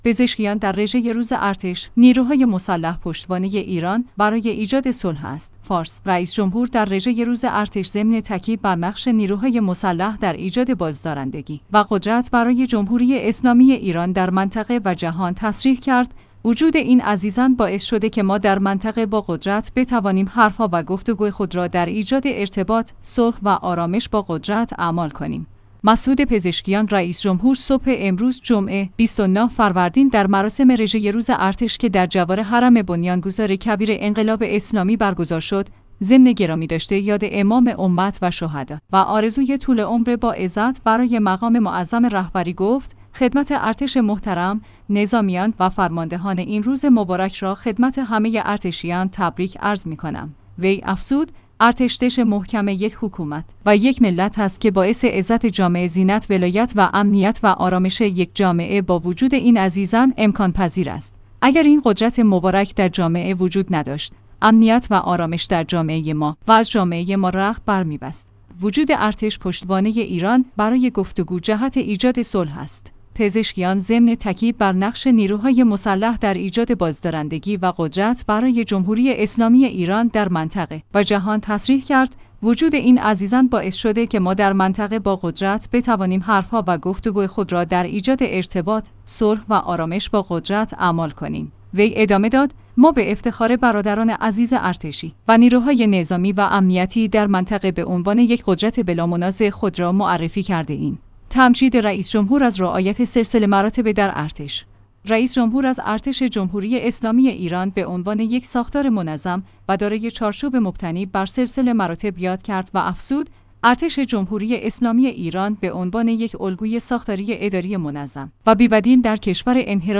پزشکیان در رژه روز ارتش: نیروهای مسلح پشتوانه ایران برای ایجاد صلح‌ است